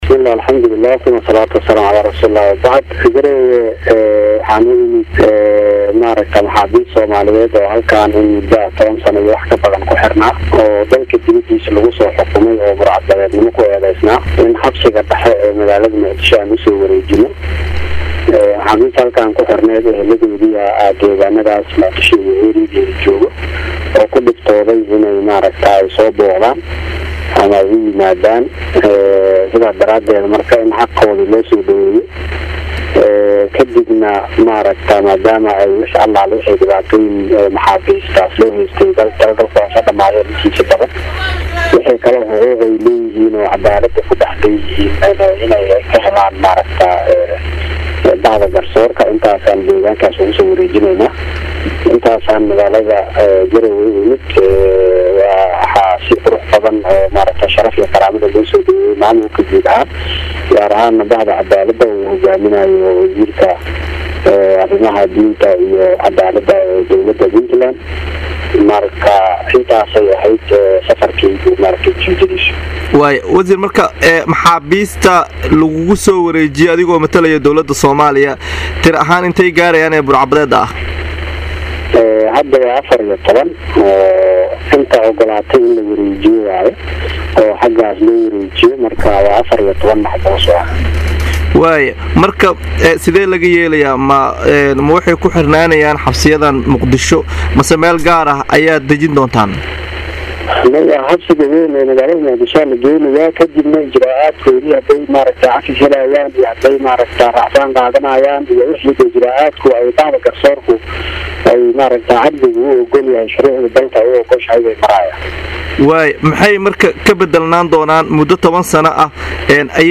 HOOS KA DHAGEYSO WAREYSIGA WASIIRKA.